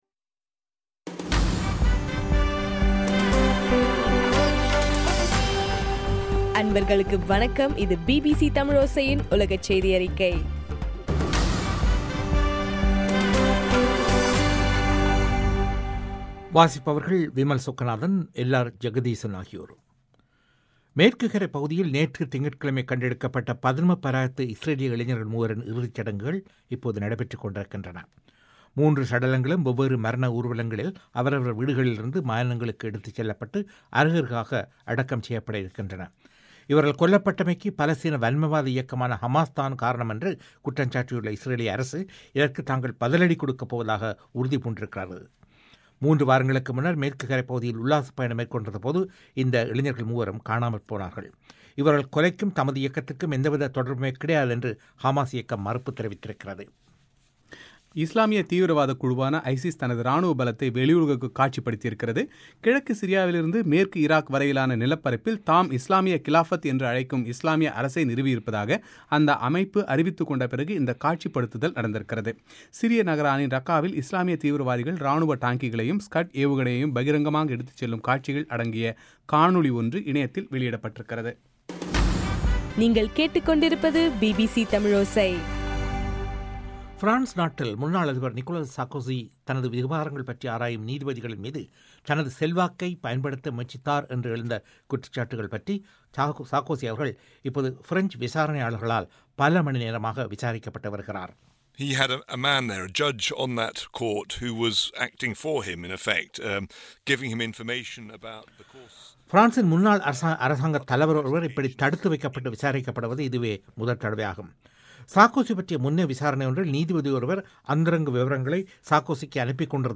இன்றைய ( ஜூலை 1) பிபிசி தமிழோசை செய்தியறிக்கை